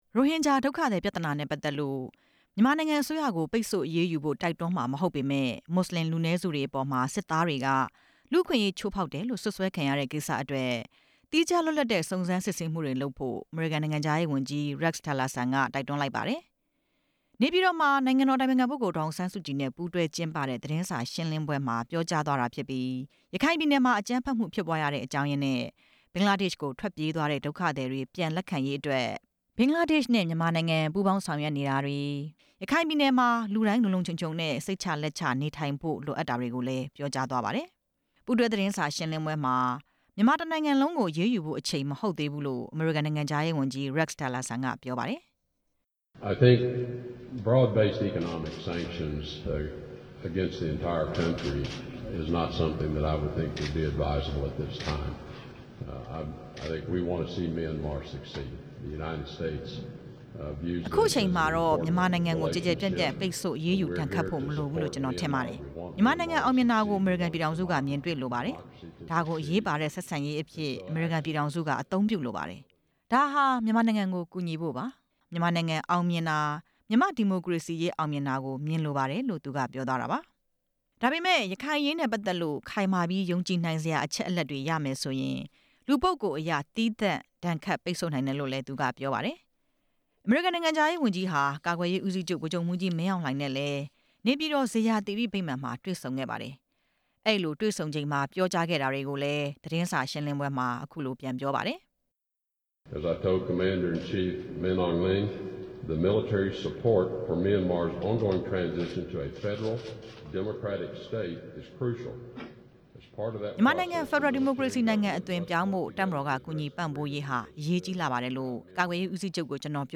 အမေရိကန်နိုင်ငံခြားရေးဝန်ကြီးနဲ့ နိုင်ငံတော် အတိုင်ပင်ခံပုဂ္ဂိုလ် သတင်းစာရှင်းလင်းပွဲ